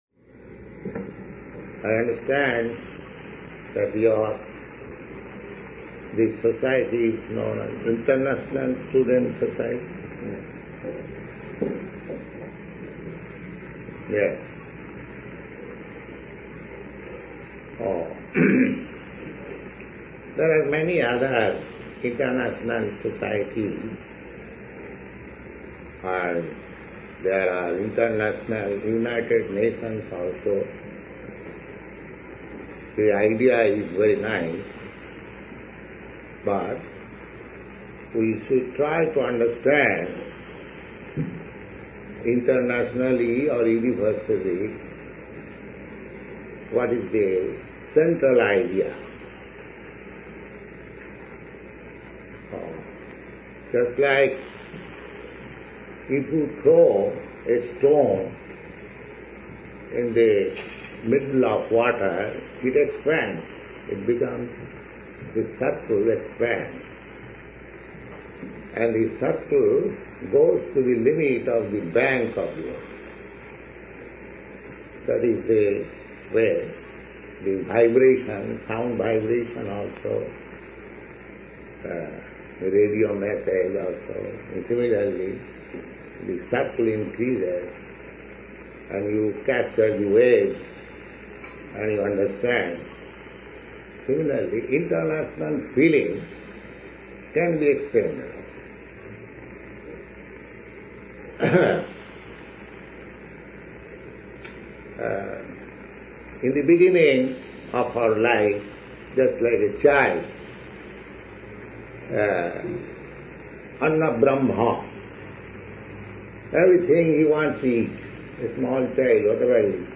Lecture at International Student Association Cambridge [near Boston]
Type: Lectures and Addresses